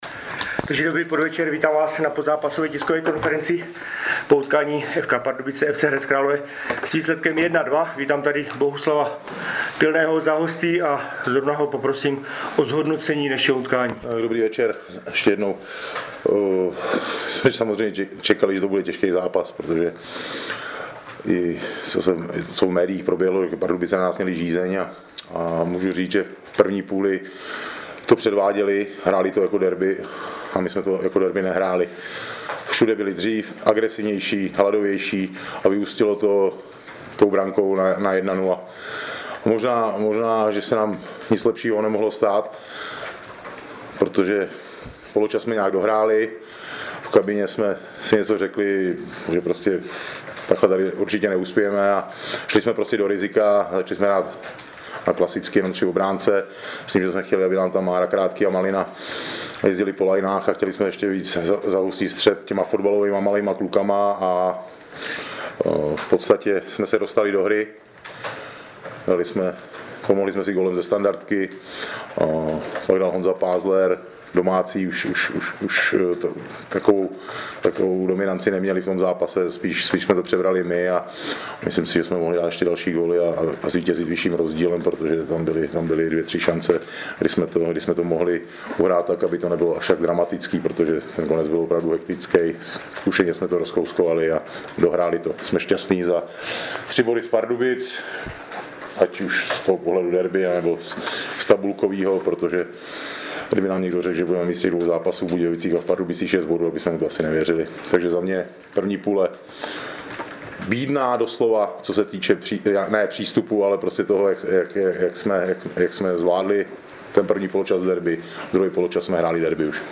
Pozápasová tisková konference
Celou tiskovou konferenci, včetně dotazů novinářů, si můžete poslechnout